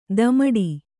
♪ damaḍi